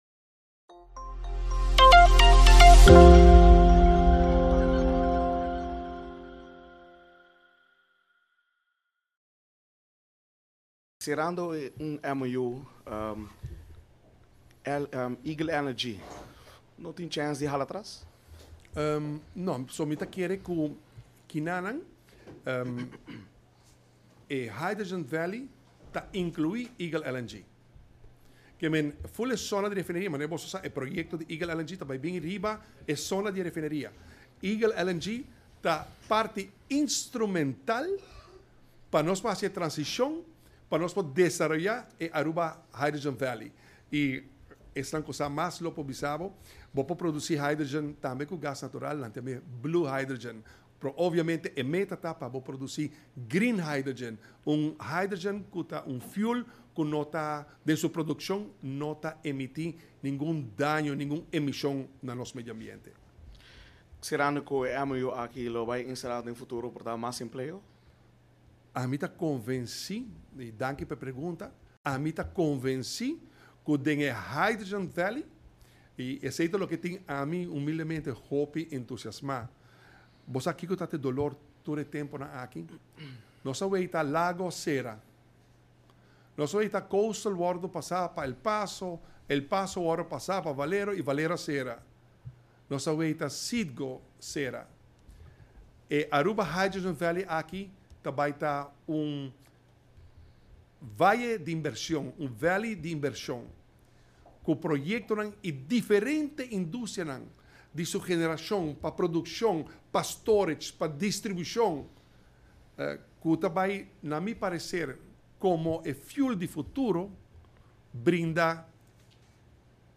Durante e rueda di prensa di diamars ultimo, for di un miembro di prensa a haya e pregunta cu si ora di cera e MOU pa Aruba Hydrogen Valley, cu si esaki no lo por conduci na Eagle LNG hala atras. Riba esaki e mandatario a splica cu EAGLE LNG lo forma parti di e Aruba Hydrogen Valley pa motibo cu e zona di refineria lo bira e zona energetico di Pais Aruba unda entre otro Eagle LNG lo wordo ubica.